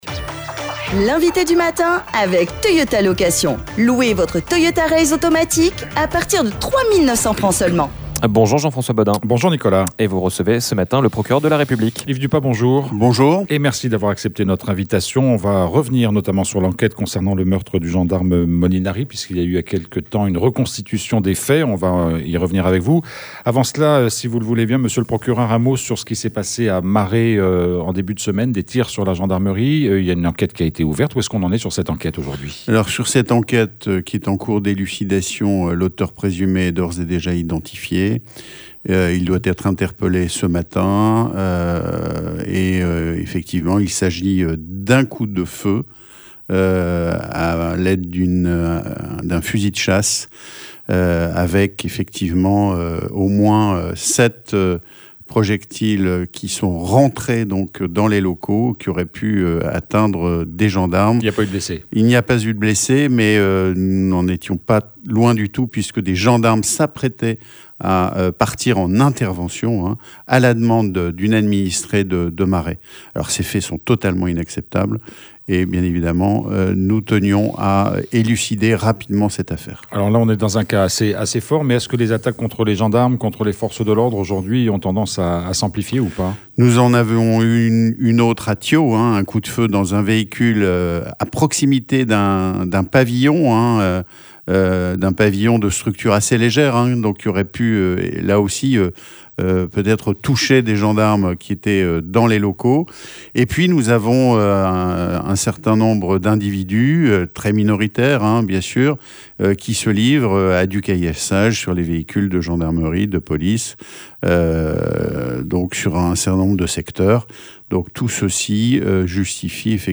C’est le procureur de la République, Yves Dupas qui est notre invité du matin. Nous revenons avec lui notamment sur cette enquête ouverte pour tentative d’homicide volontaire sur des gendarmes à Tadine…